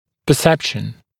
[pə’sepʃn][пэ’сэпшн]понимание, осознание, восприятие